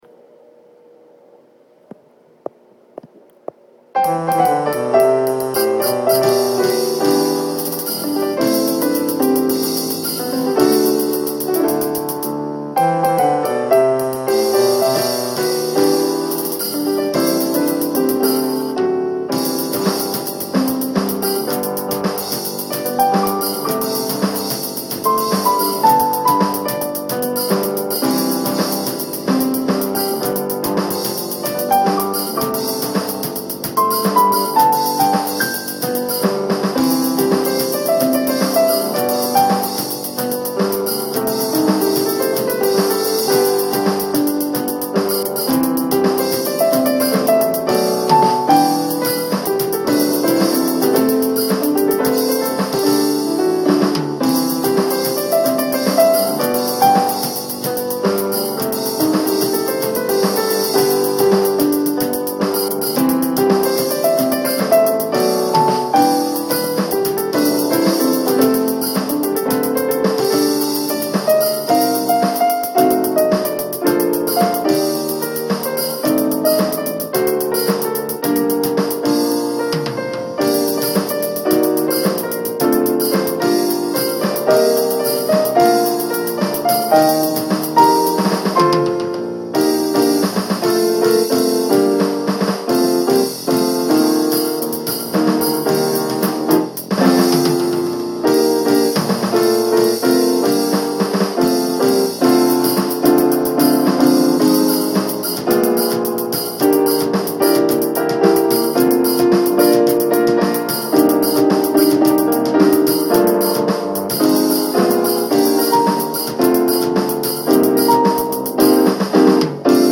피아노
몇번 재녹음하다가 힘들어서 결국 몇군데 틀린채로 녹음을 마무리 했습니다.